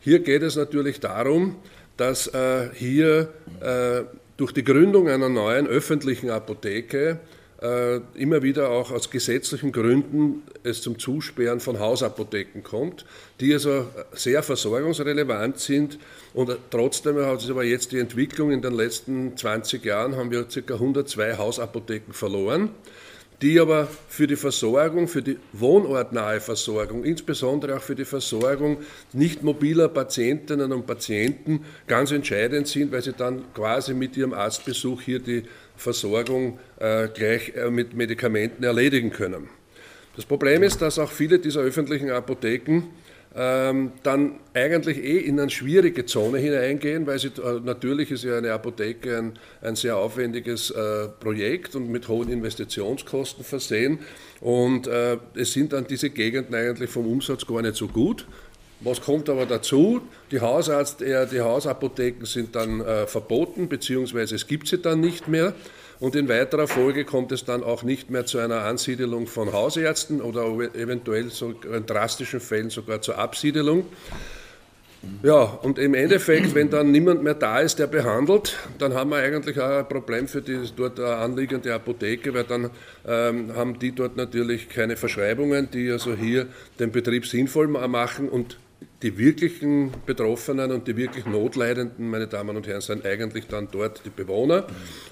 O-Töne (MP3)
Dr. Johannes Steinhart